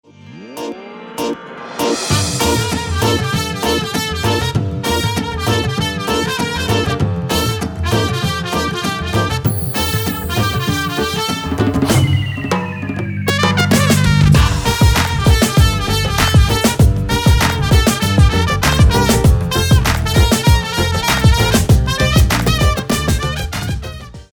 جلوه های صوتی
مجموعه بی کلام رینگتون موبایل